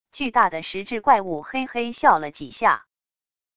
巨大的石质怪物嘿嘿笑了几下wav音频